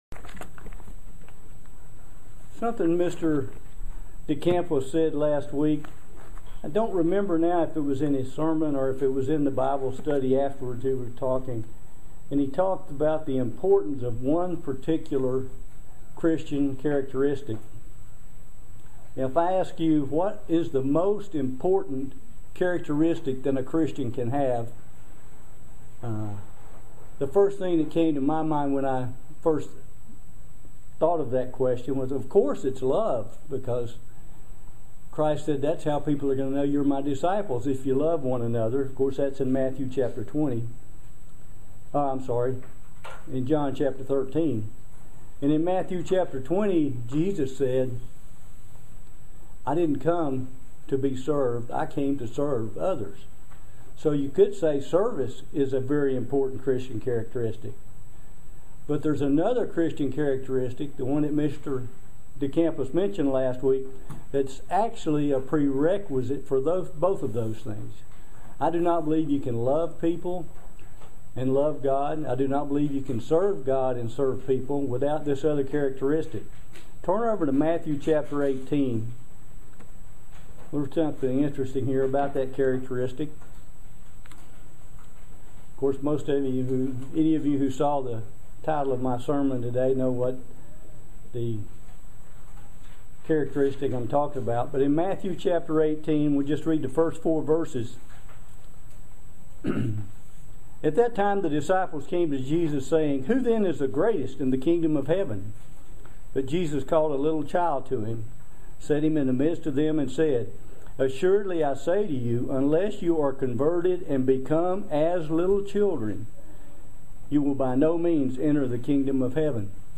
Join us for this excellent video sermon on the subject of humility. How important is our humility to God?